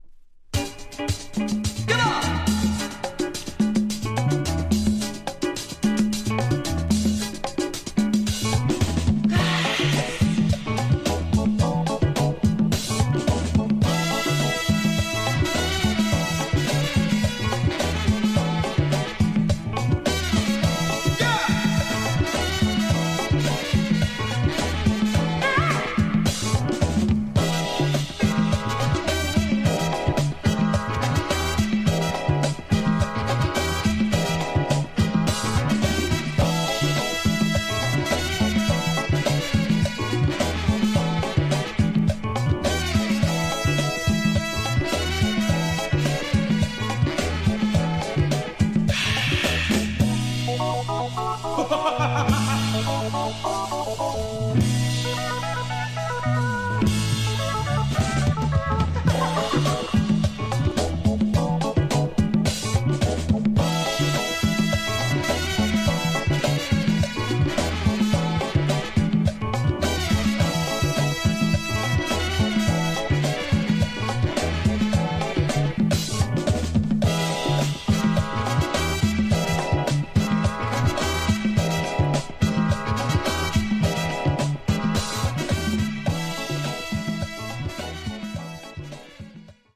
Tags: Mexico , Latin Funk